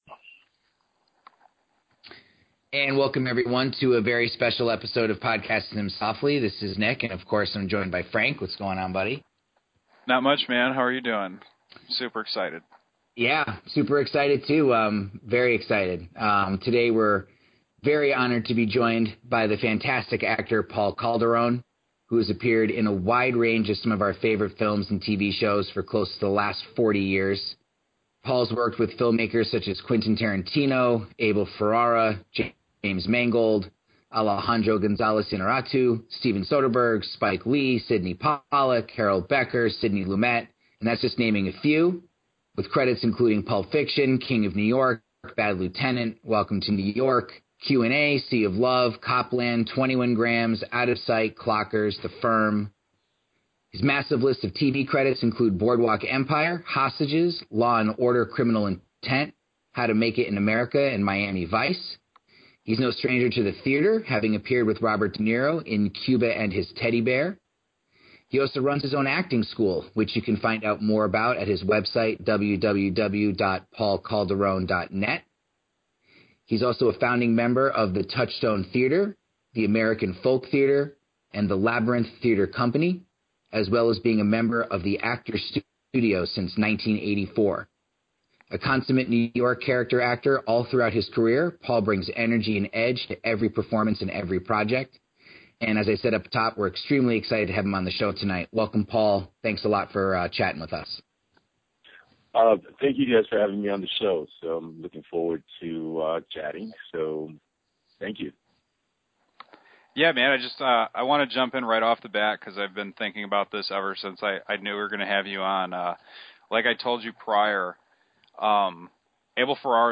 Podcasting Them Softly is honored to present a chat with veteran actor Paul Calderon, who has appeared in a wide range of some of our favorite films and TV shows for close to the last 40 years.